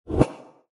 Звуки облака, мыслей
Звуковой эффект для диалогового текста в игре